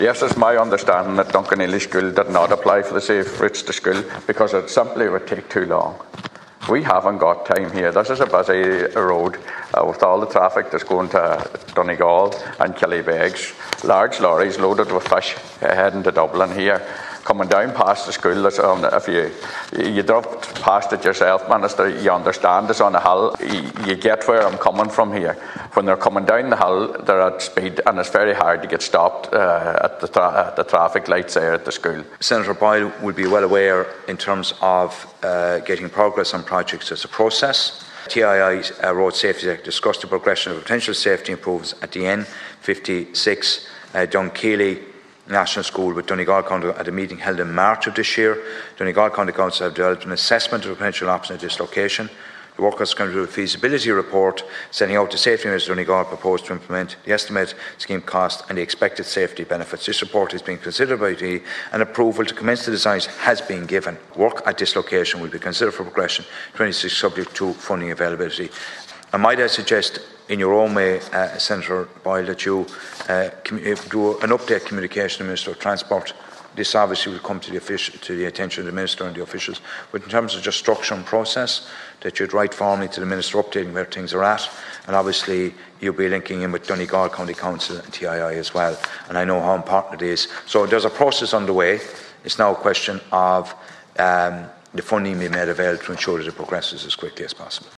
The Seanad has been told that Donegal County Council has been given clearance to begin design on a safety scheme for Dunkineely National School near Killybegs.
Minister O’Donnell said the school hasn’t applied for inclusion in the Safe Routes to School Scheme, Senator Boyle told him they don’t have time to wait…….